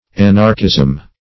Anarchism \An"arch*ism\, n. [Cf. F. anarchisme.]